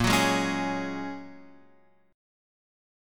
A#m7#5 chord